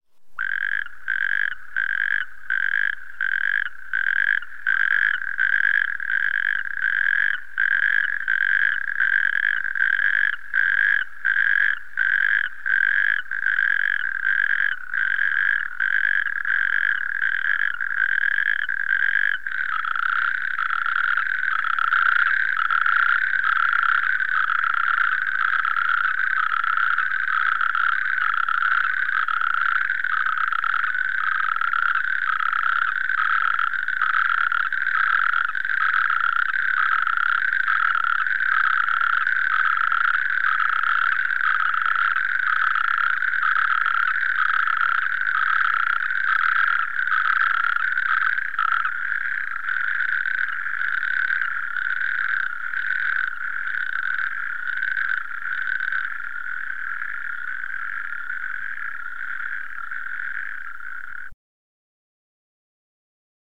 Strandtudse (Epidalea calamita)
Lyt til strandtudsen .
strandtudse.mp3